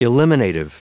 Prononciation audio / Fichier audio de ELIMINATIVE en anglais
Prononciation du mot : eliminative
eliminative.wav